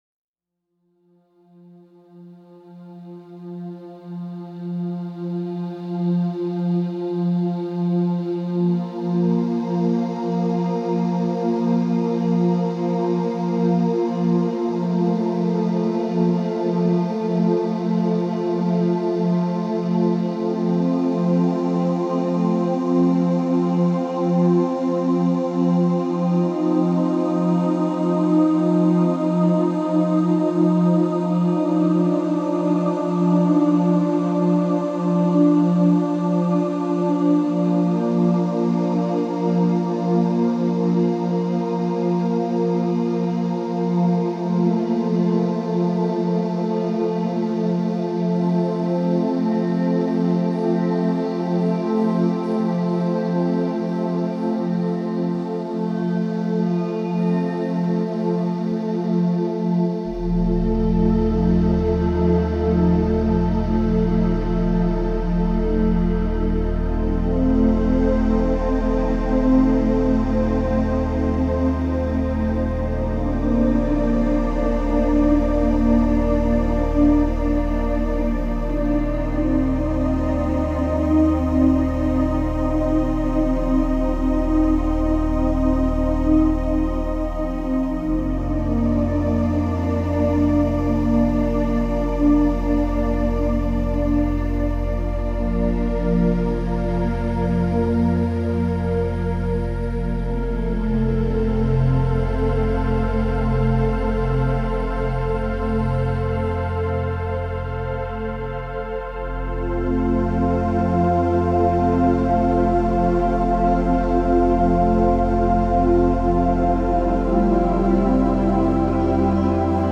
Music for pre-sleep, and relaxation, and stress relief.